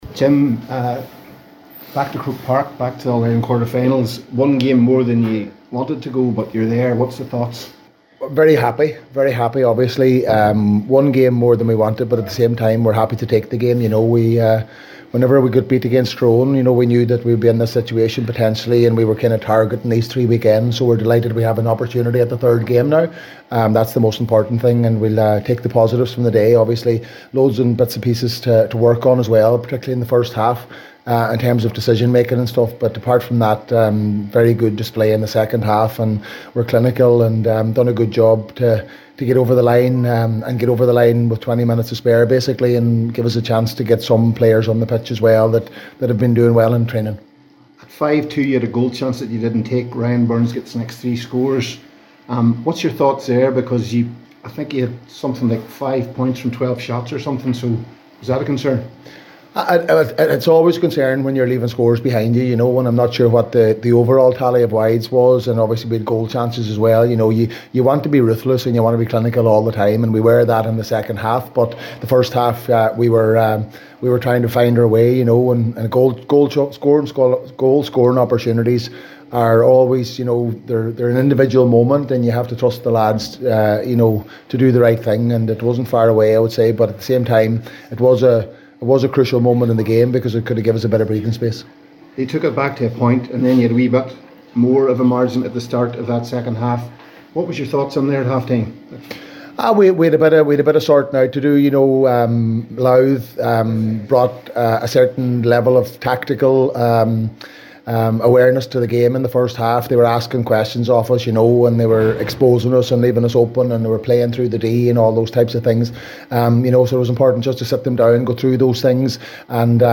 Donegal manager Jim McGuinness
After the game, McGuinness told